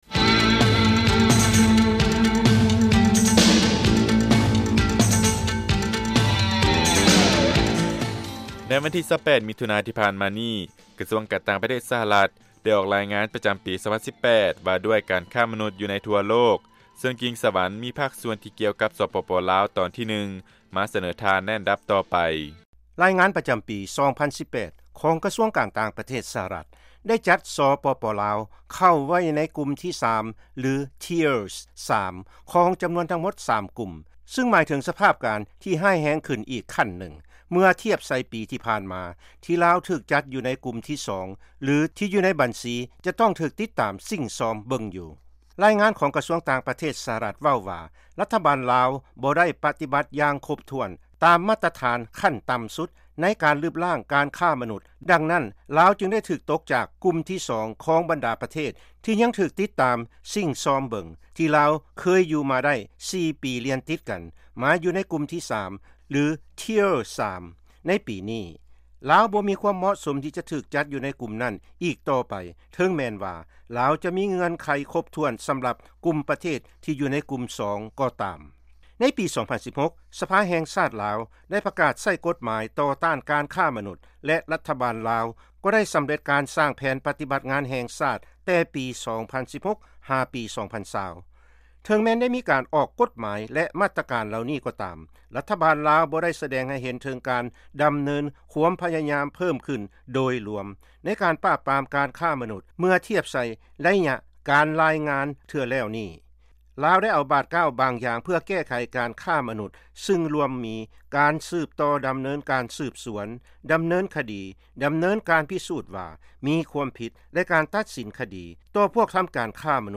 ເຊີນຟັງລາຍງານພິເສດ ກ່ຽວກັບການຄ້າມະນຸດ ໃນ ສປປ ລາວ.